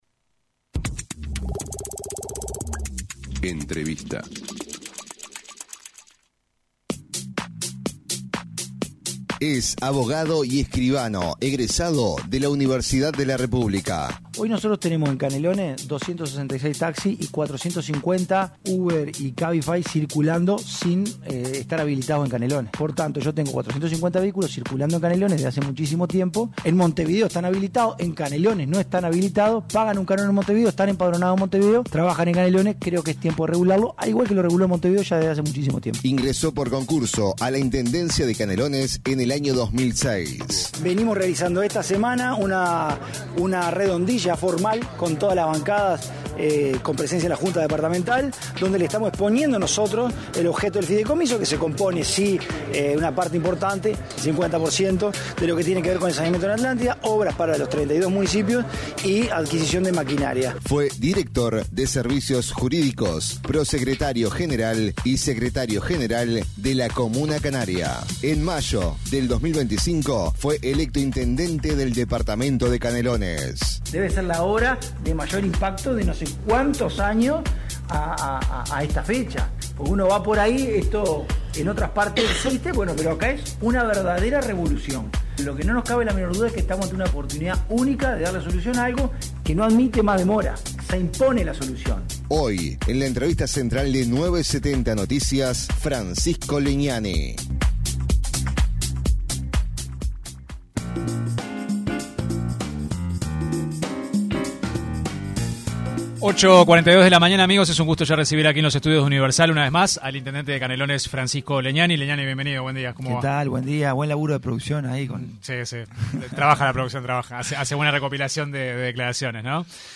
El intendente de Canelones, en una entrevista con 970 Noticias, dijo que el fideicomiso aprobado en la Junta Departamental, el cual será destinado a obras y maquinarias viales y saneamiento, concretamente en la zona de Atlántida.